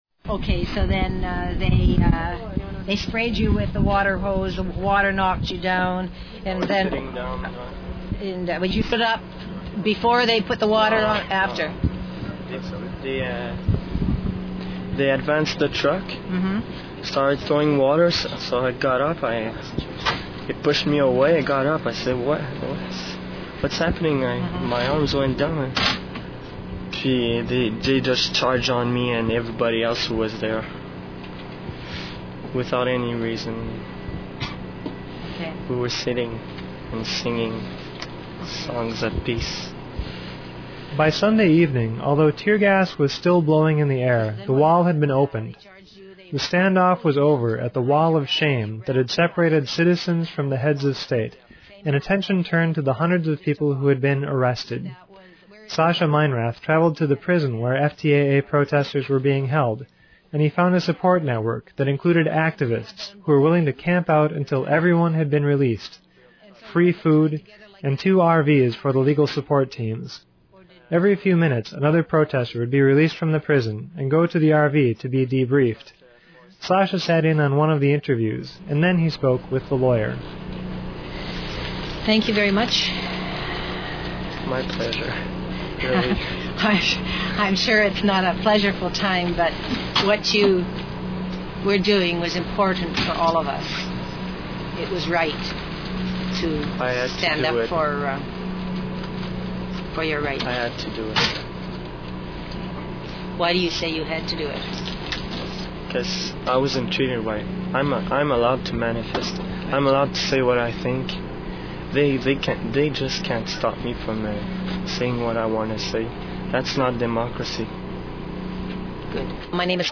Just days after arriving home from Quebec City and the protests surrounding the Summit of the Americas, Urbana-Champaign Independent Media Center journalists compiled a half-hour document of their journey and experiences on the streets of Quebec City. Originally aired April 30 on community radio WEFT, during the IMC Newshour.